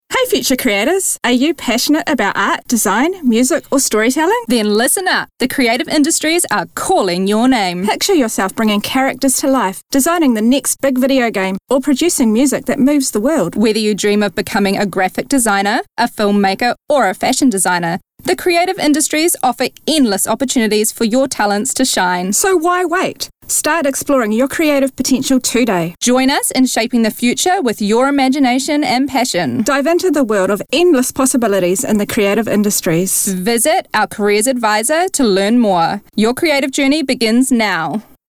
Priority-One-Commercial-D.mp3